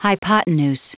Prononciation du mot hypotenuse en anglais (fichier audio)
Prononciation du mot : hypotenuse